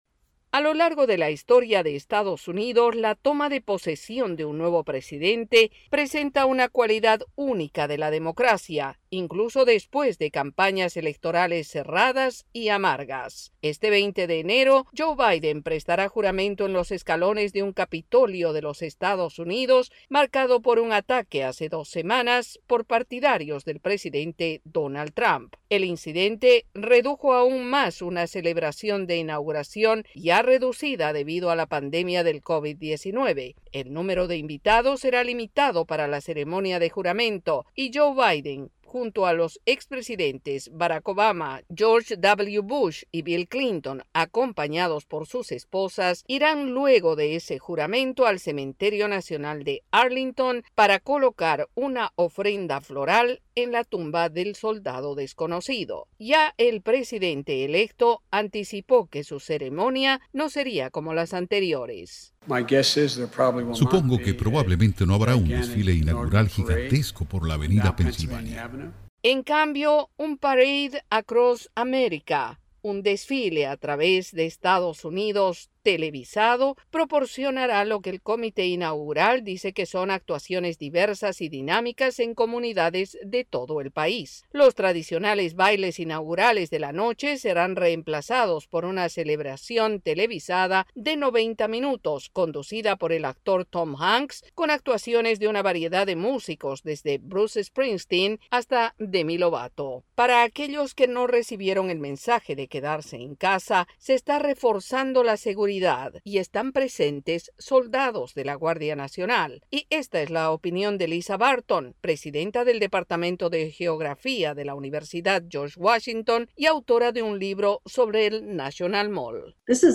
Excepto en casos raros, la toma de posesión de un nuevo presidente simboliza la tradición estadounidense de una transferencia pacífica del poder, pero ahora esperamos algo diferente. El informe